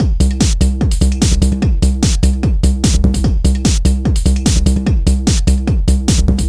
高能量舞蹈3
Tag: 148 bpm Techno Loops Drum Loops 1.09 MB wav Key : Unknown